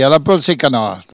Elle crie pour appeler les canards